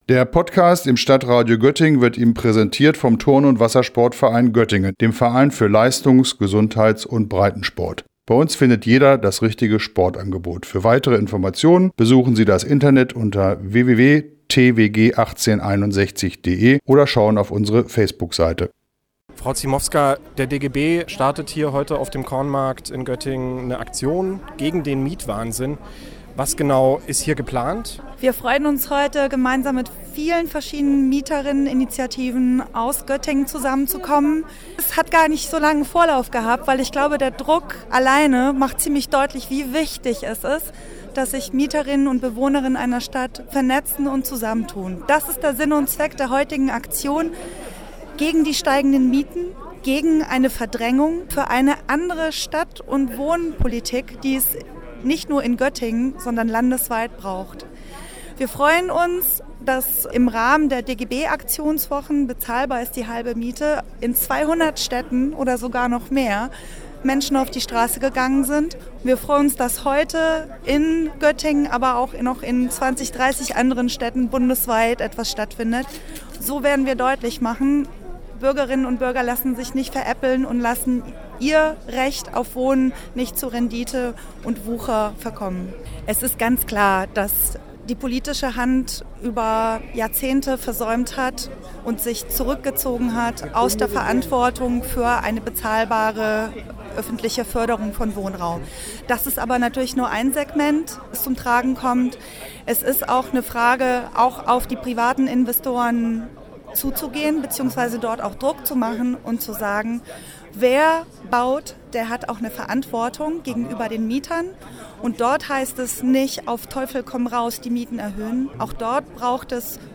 Der DGB hat mit einer Aktion auf dem Kornmarkt versucht, am vergangenen Samstag auf die Lage aufmerksam zu machen und mit den Menschen auf der Straße ins Gespräch zu kommen.